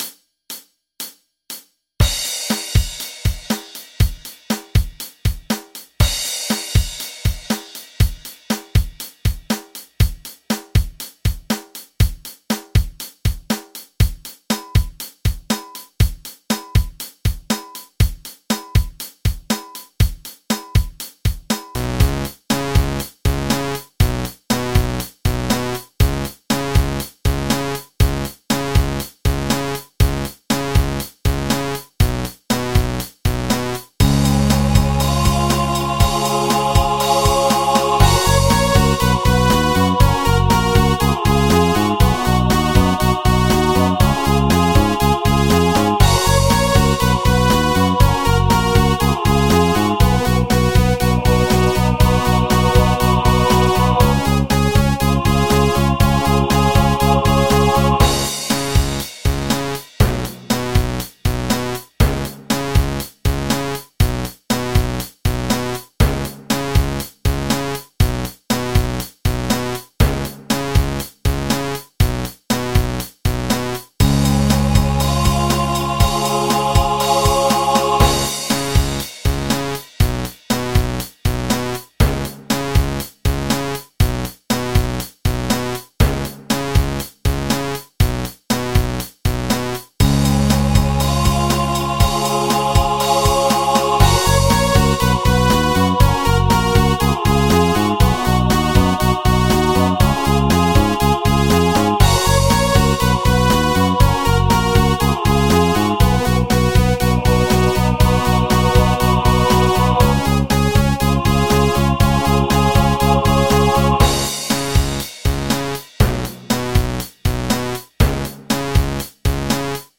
MIDI 32.22 KB MP3 (Converted)